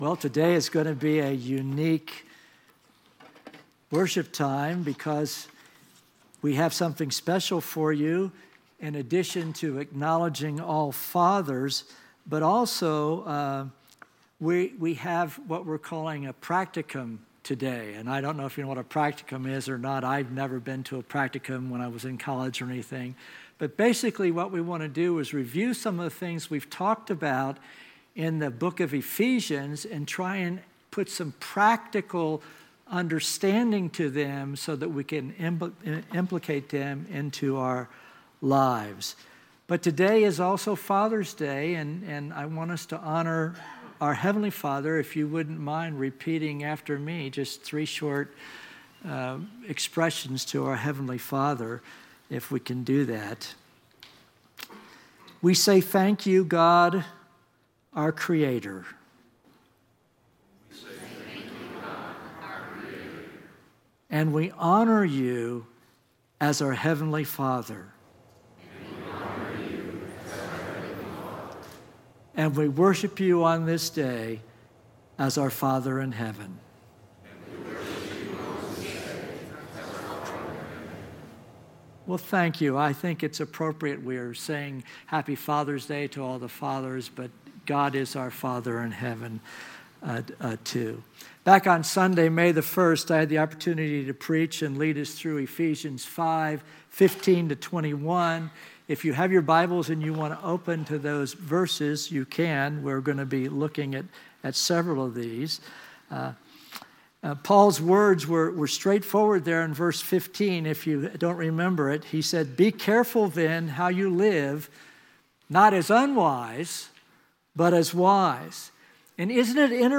Today in our practicum session we will take a summary look at the material we've been learning the last several weeks from our study of Ephesians.